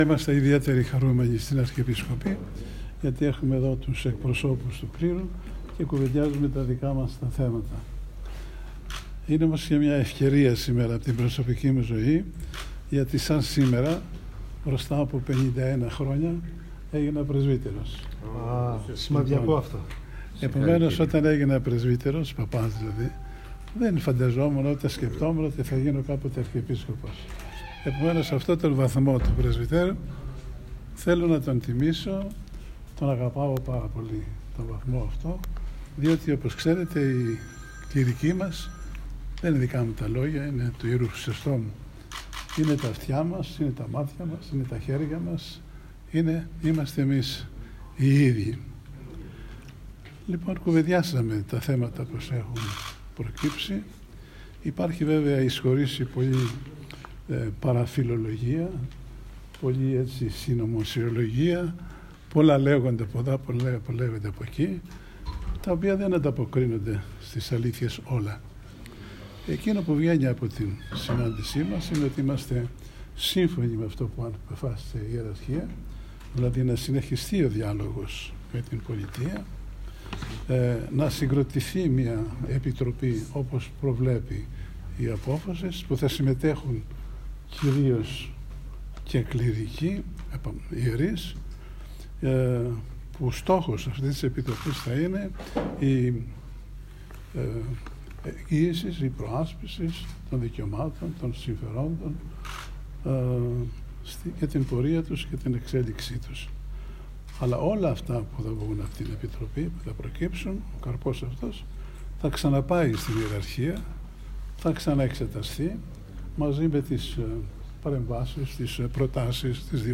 Παραθέτουμε ηχητικά αποσπάσματα των πρώτων δηλώσεων μετά το πέρας της συνάντησης Αρχιεπισκόπου Ιερωνύμου και Ιερού Συνδέσμου Κληρικών Ελλάδος, όπως τις κατέγραψε το Πρακτορείο Εκκλησιαστικό Εισήδεων ΟΡΘΟΔΟΞΙΑ: